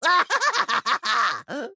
*大笑*